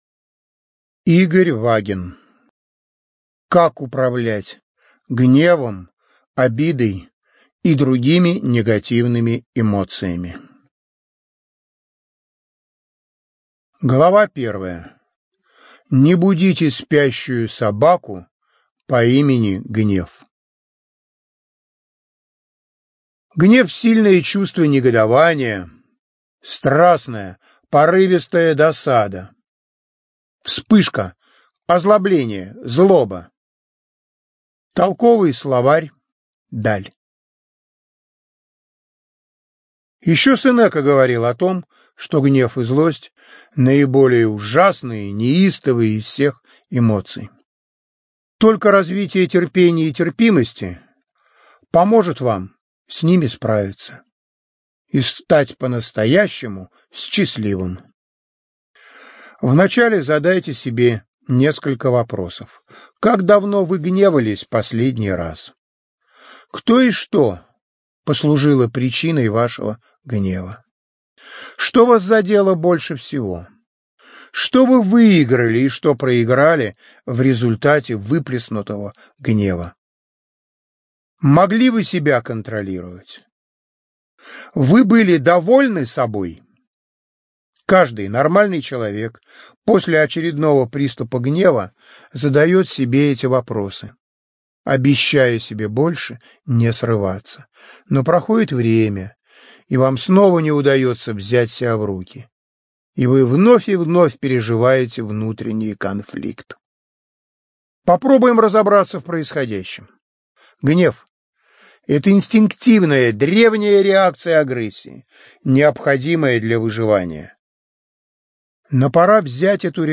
ЧитаетАвтор